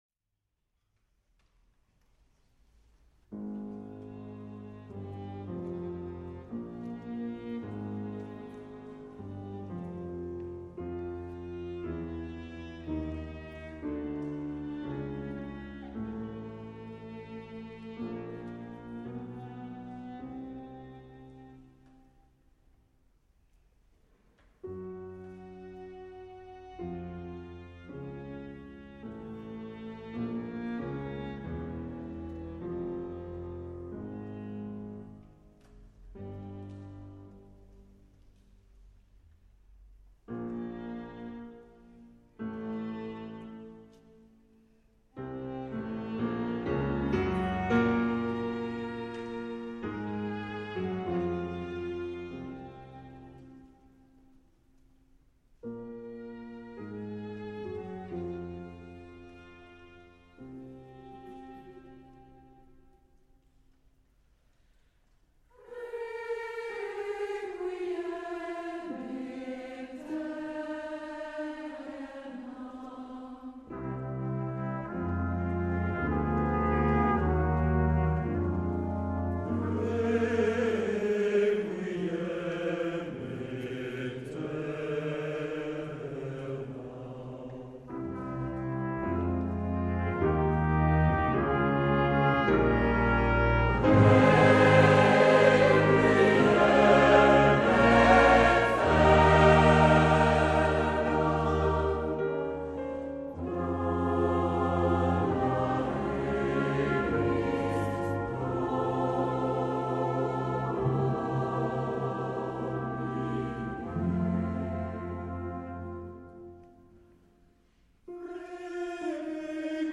Quelques extraits de concerts des dernières années